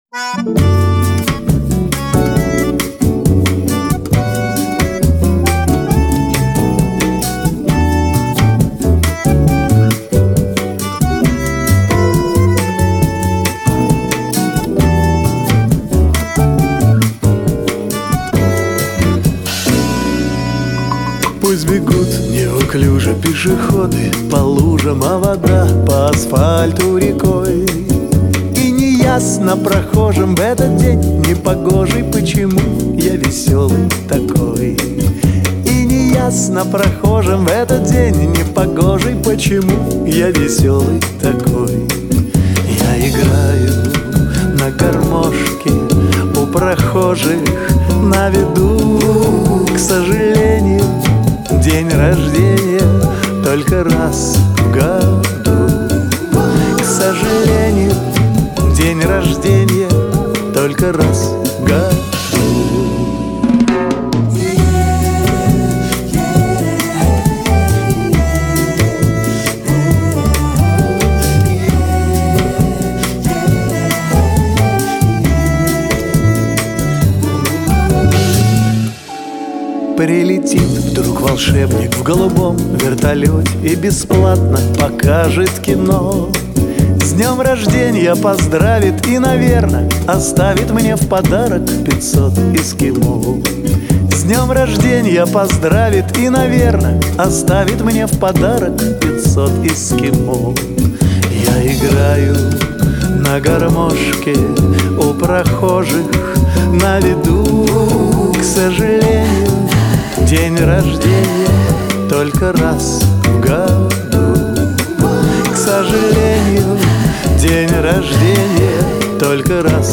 Стиль — Детские песни